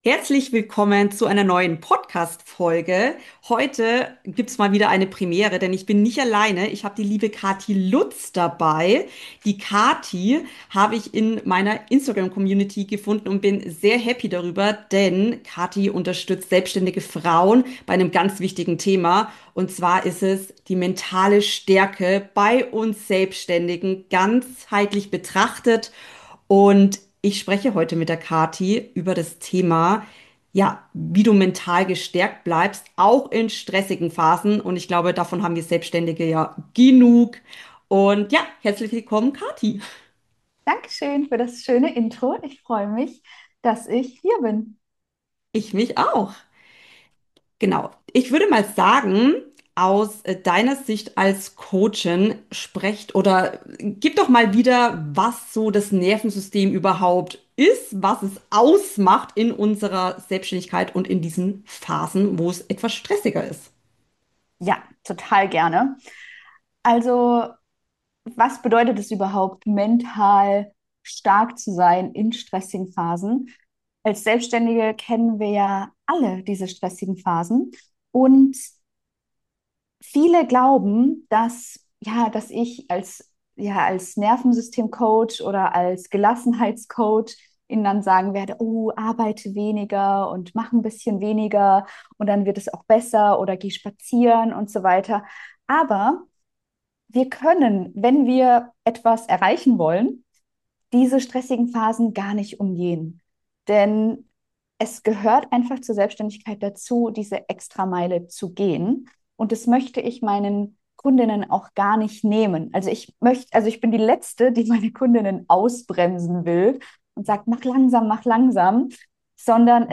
Und wie viel Stress ist eigentlich noch „okay“ fürs Nervensystem – und ab wann wird’s kritisch? In Teil 2 dieses Interview Formates sprechen zeigen wir dir 5 konkrete Wege, wie du mental gestärkt dein Business in die Sichtbar bringst.